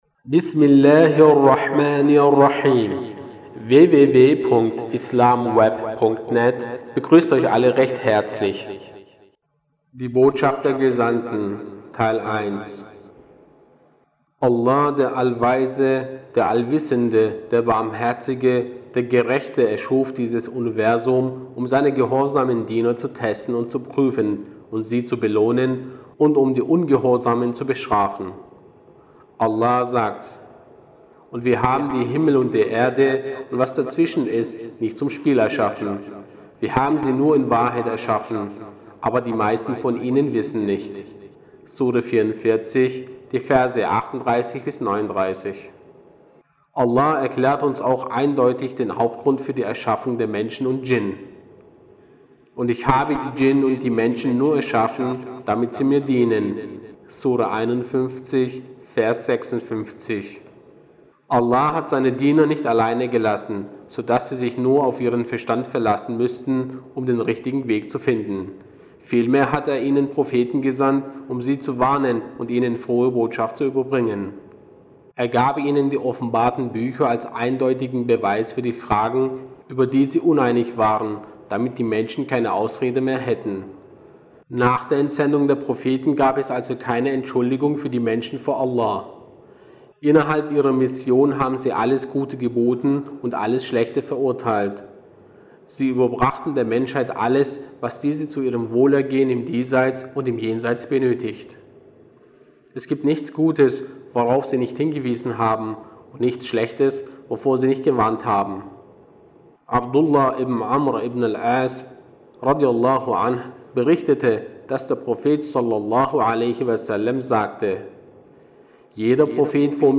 Lesungen